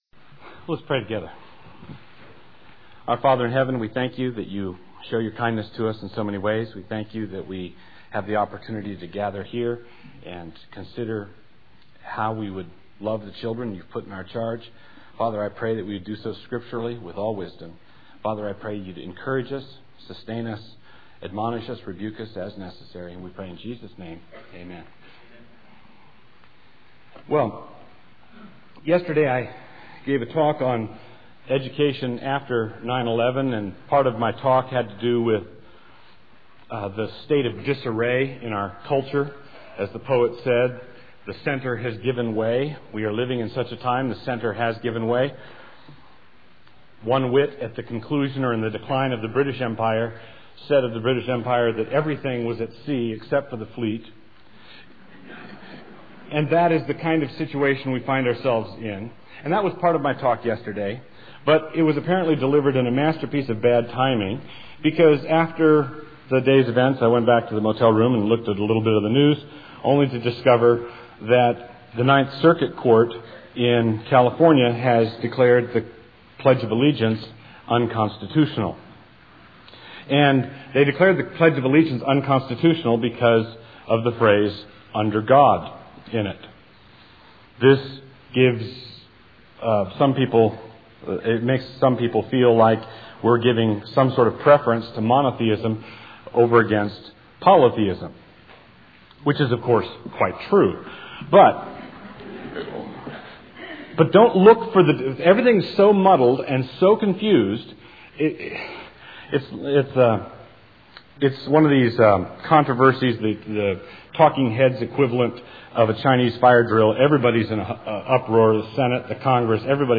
2002 Workshop Talk | 0:55:59 | Leadership & Strategic
Mar 11, 2019 | Conference Talks, Leadership & Strategic, Library, Media_Audio, Workshop Talk | 0 comments